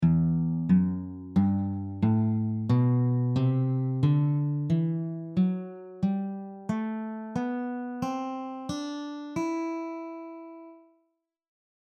Each scale below will cover two octaves on the guitar in standard notation with tabs and audio examples included.
E minor scale
The notes of the E natural minor scale are E, F#, G, A, B, C, and D.
E-minor-Em-scale-audio.mp3